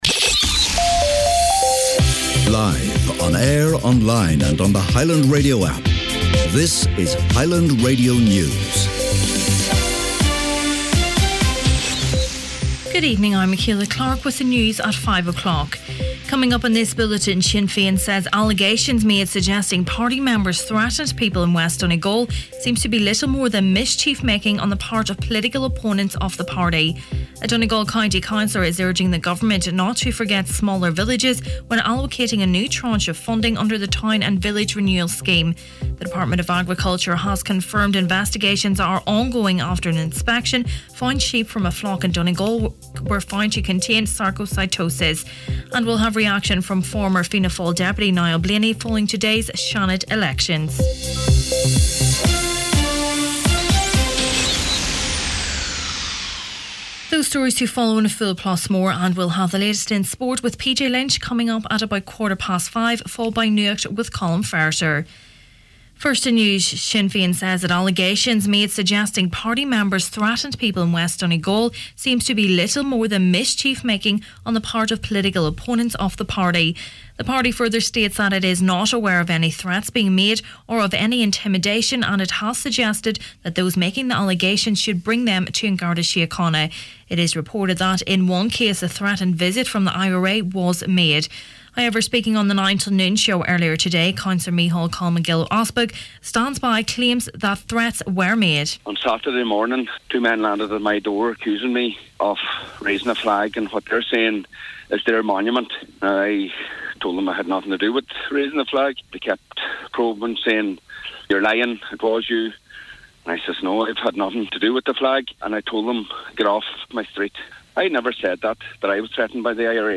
Main Evening News, Sport, Nuacht and Obituaries Friday 27th April